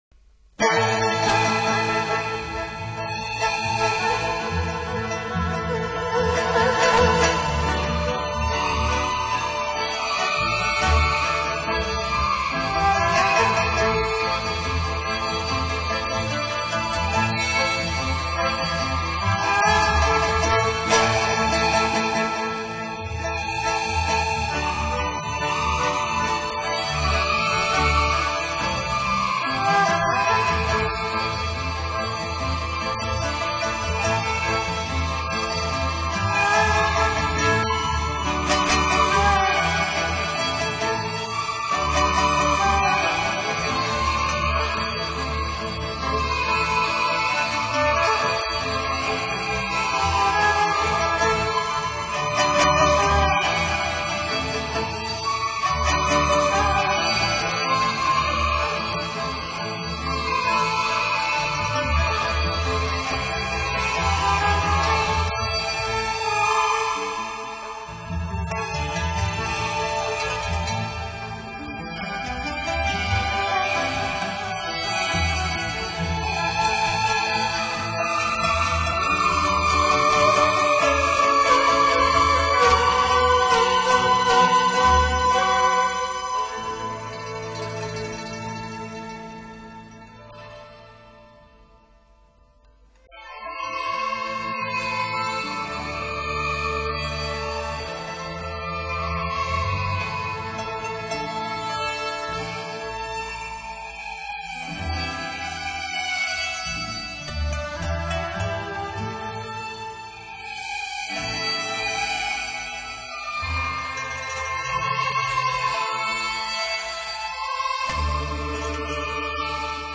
light]中国民乐国粹